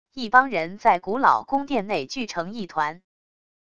一帮人在古老宫殿内聚成一团wav音频